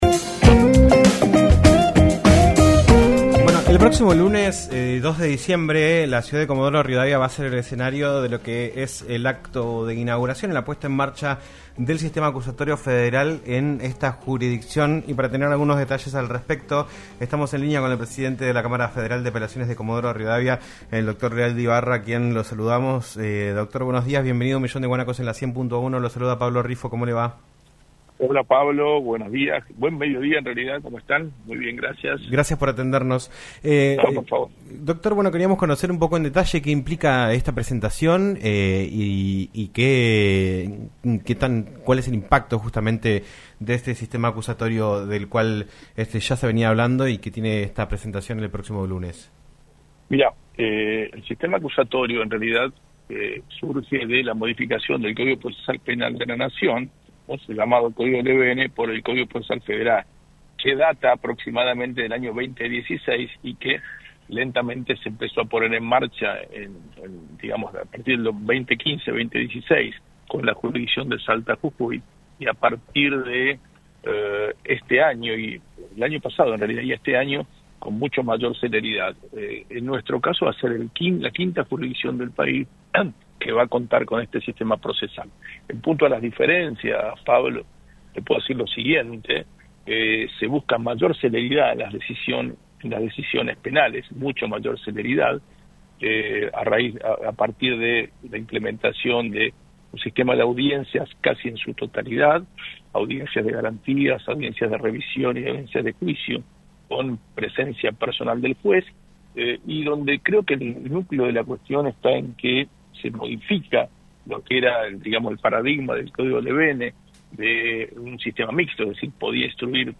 Javier Leal De Ibarra, presidente de la Cámara de Apelaciones de Comodoro Rivadavia, habló en "Un Millón de Guanacos" por LaCienPuntoUno sobre lo que será el próximo lunes la presentación del Sistema Acusatorio Federal en la ciudad con la presencia del ministro de Justicia, Mariano Cúneo Libarona. Además, se refirió al proyecto de Ficha Limpia y la condena contra Cristina Fernández de Kirchner.